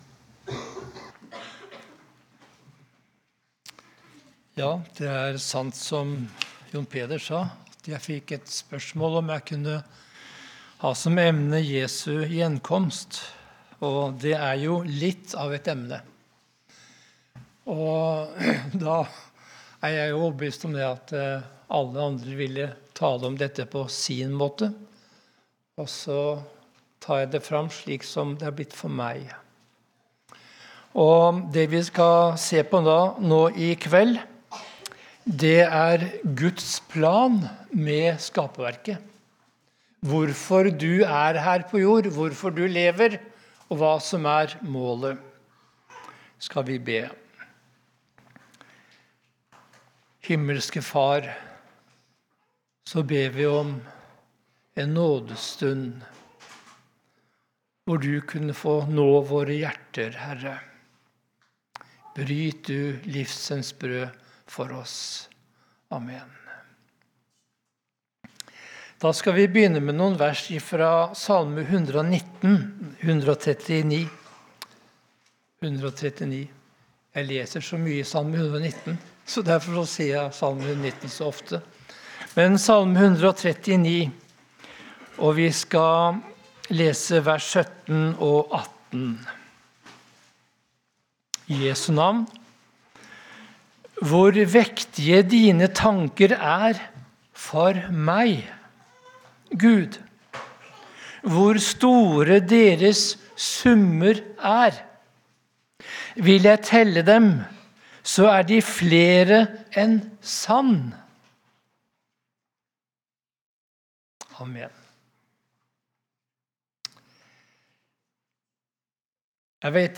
29.12.2024 | Nyttårsleir Fossnes 2024/2025